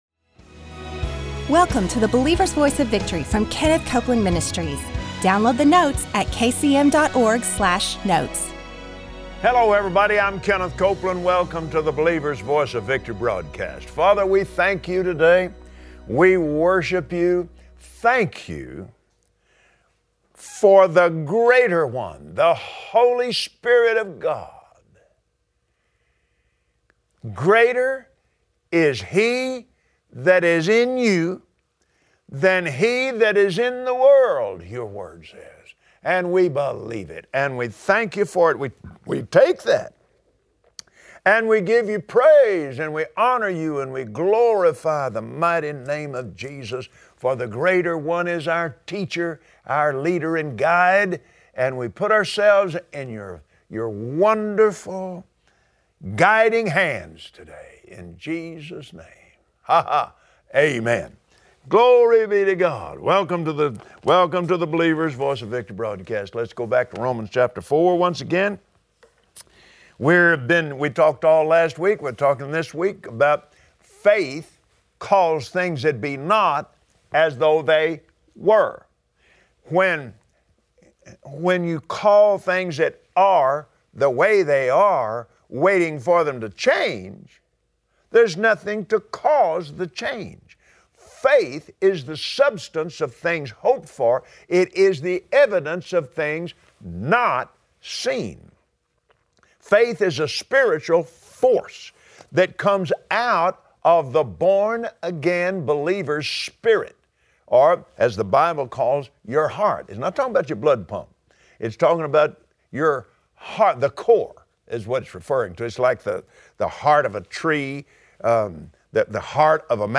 Are you ready for the power of God to manifest in your life? Join Kenneth Copeland as he brings instructions on how to receive the Holy Spirit today.